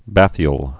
(băthē-əl)